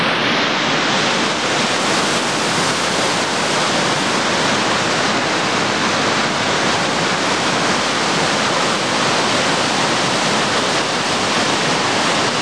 waterfall.aiff